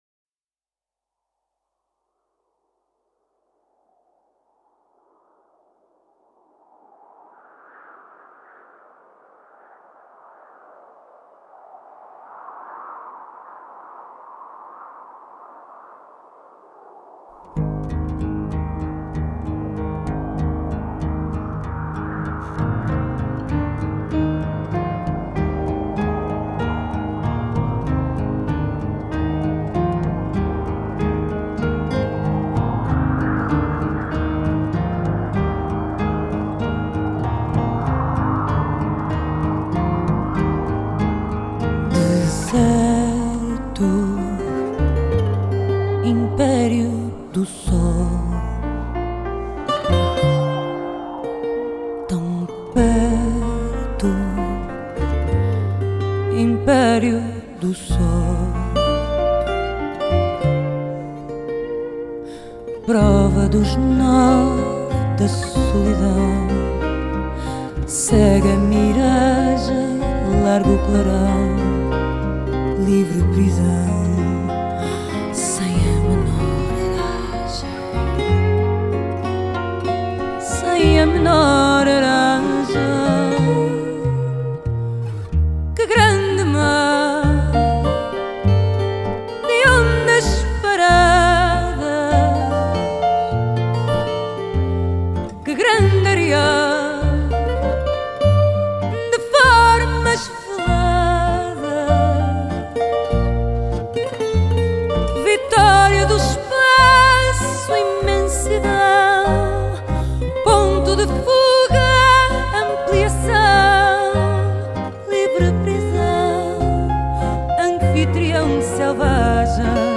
Тема: meu fado